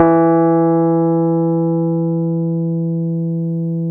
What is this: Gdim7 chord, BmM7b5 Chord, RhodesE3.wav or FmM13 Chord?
RhodesE3.wav